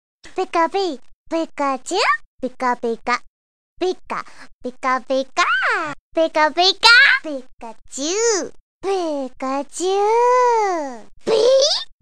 Kategorie Gry